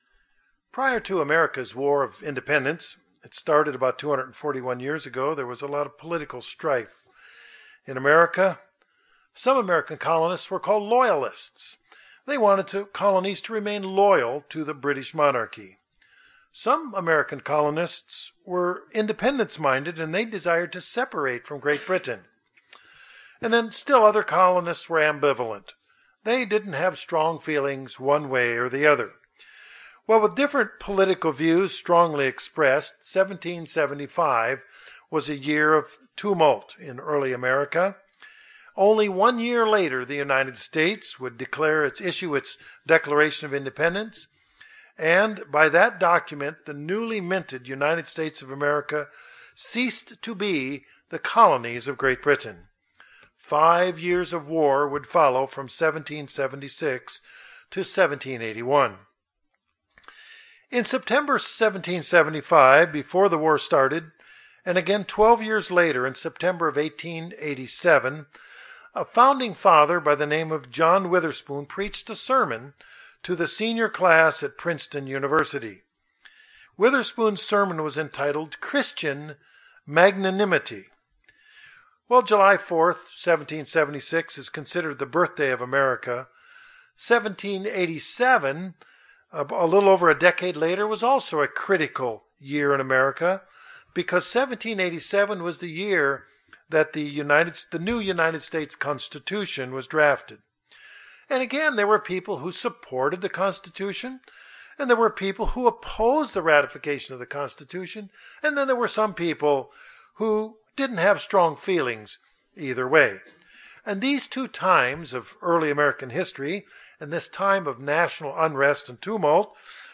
Print What is magnanimity and how can its definition apply to the life of a Christian? sermon Studying the bible?